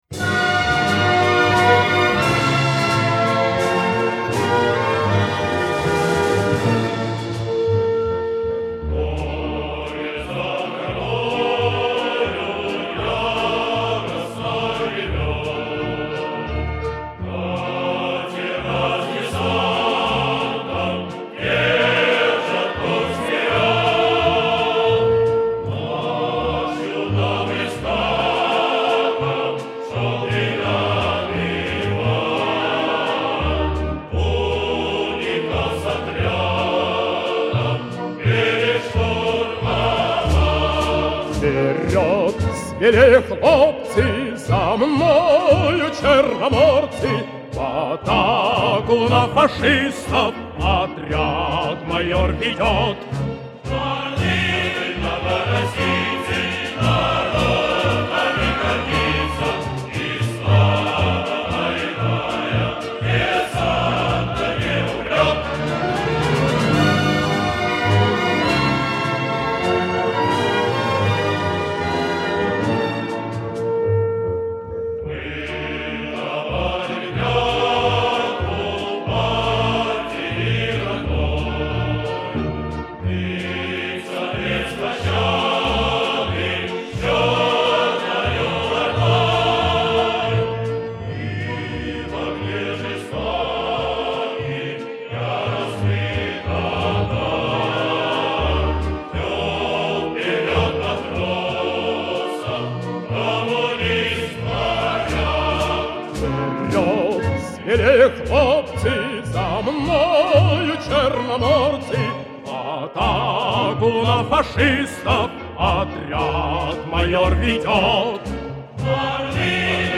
хор